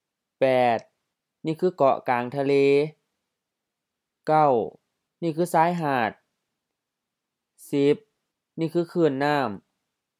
Basic vocabulary — page 18 — Series A, pictures 08-10: island, beach, wave
ซายหาด sa:i-ha:t HR-LF ชายหาด beach
คื้น khʉ:n HF คลื่น wave